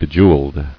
[be·jew·eled]